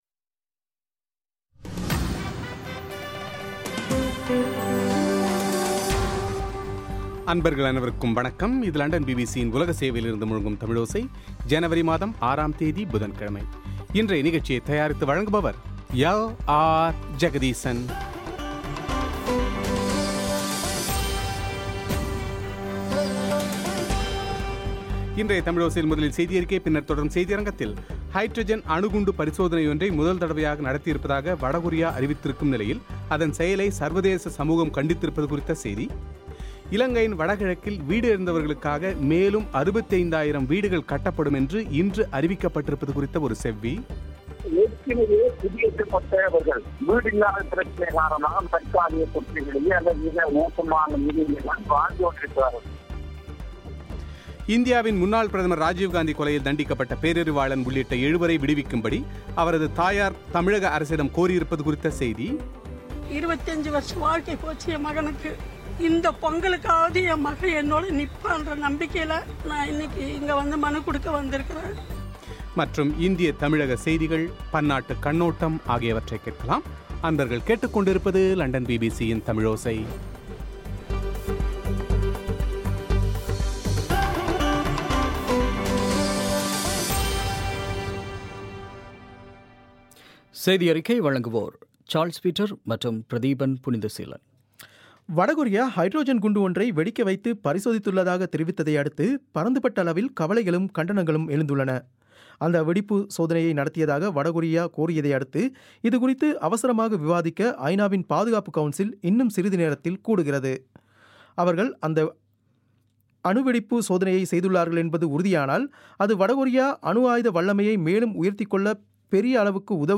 இலங்கையின் வடகிழக்கே போரினால் பாதிக்கப்பட்ட மக்களுக்கு அடுத்த மூன்றாடுகளுக்குள் 65,000 வீடுகளைக் கட்டித்தரும் திட்டம் ஒன்று தீட்டப்பட்டுள்ளதாக அரசு இன்று அறிவித்துள்ளது குறித்து மீள்குடியேற்றத்துறையின் துணை அமைச்சர் ஹிஸ்புல்லாஹ்வின் செவ்வி;